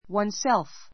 oneself wʌnsélf ワン セ るふ